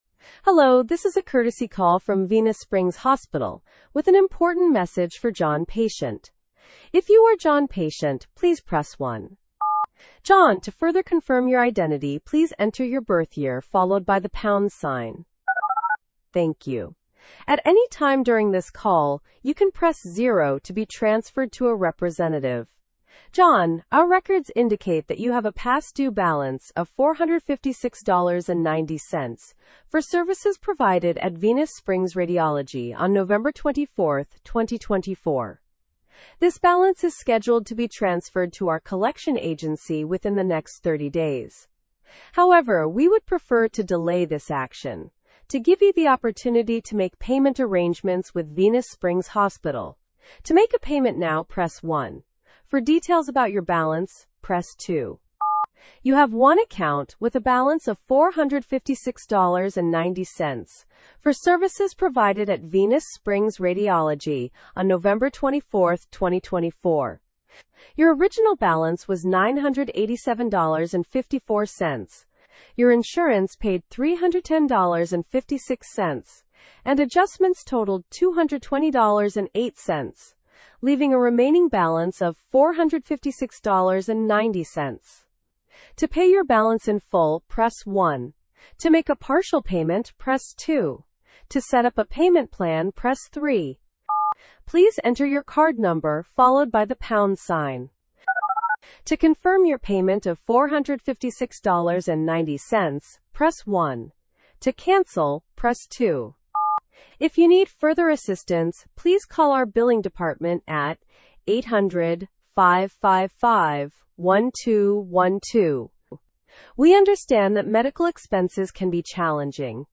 Sample Call